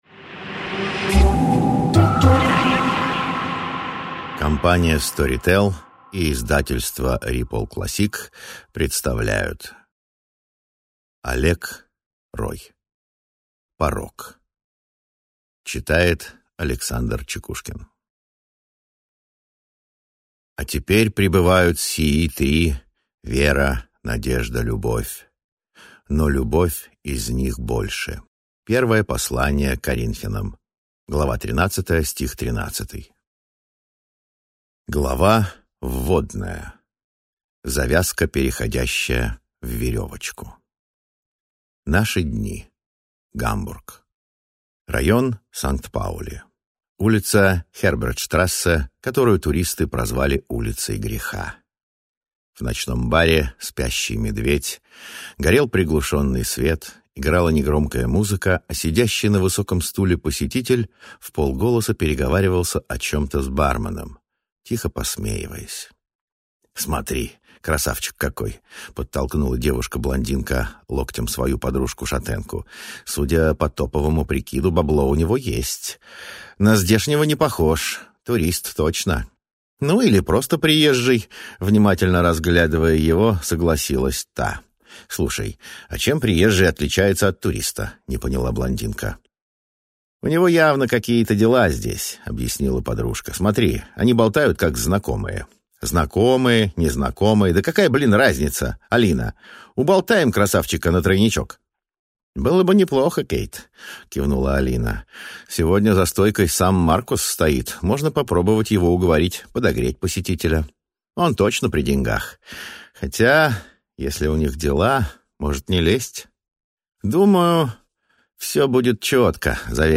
Аудиокнига Порог | Библиотека аудиокниг